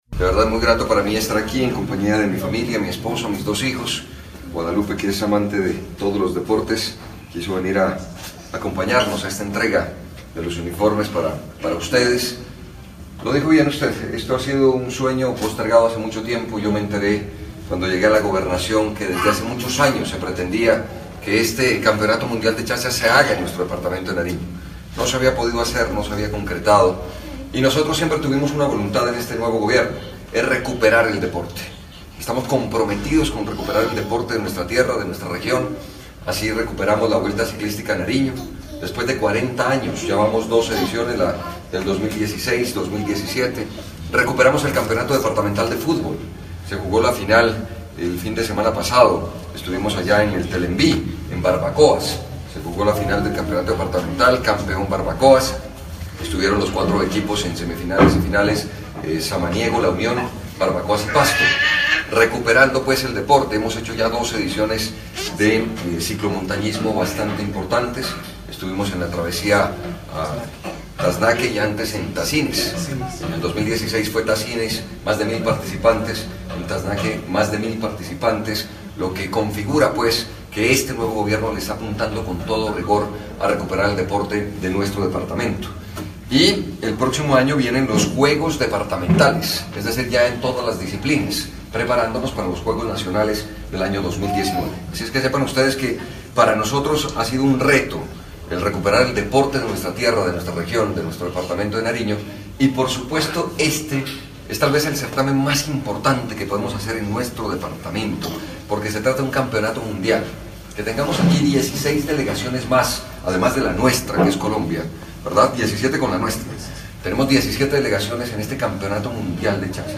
El mandatario Camilo Romero en compañía de su familia, brindó un emotivo saludo a los deportistas que representarán a Colombia en el IX Campeonato Mundial de Pelota a Mano - Chaza, que se cumple en Nariño desde el 18 hasta el 26 de noviembre.